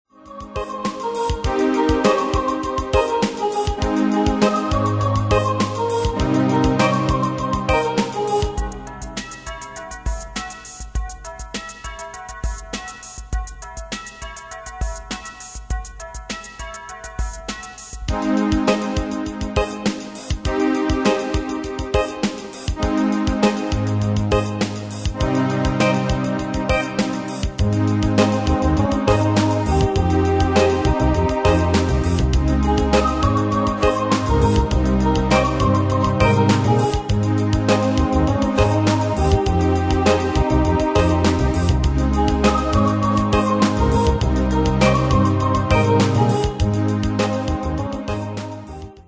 Style: Dance Ane/Year: 1991 Tempo: 101 Durée/Time: 4.23
Pro Backing Tracks